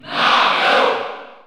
Category: Crowd cheers (SSBU) You cannot overwrite this file.
Mario_Cheer_French_NTSC_SSBU.ogg.mp3